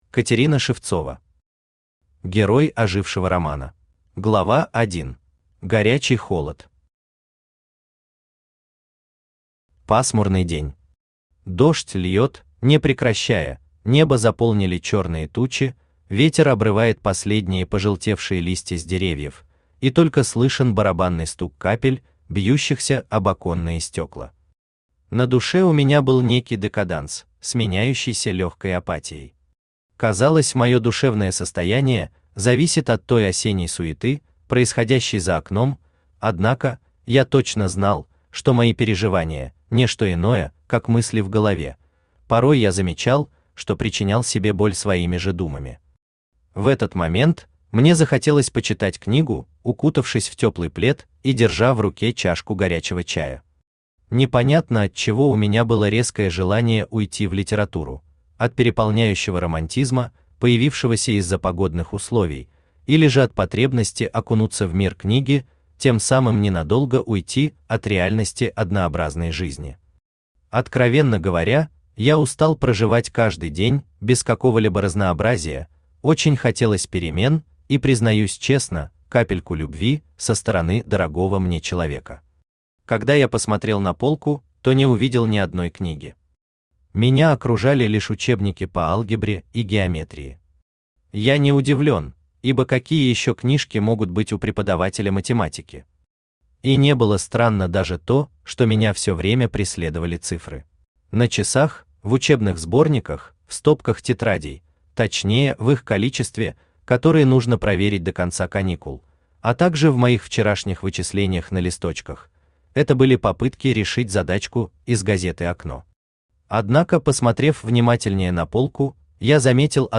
Аудиокнига Герой ожившего романа | Библиотека аудиокниг
Aудиокнига Герой ожившего романа Автор Катерина Евгеньевна Шевцова Читает аудиокнигу Авточтец ЛитРес.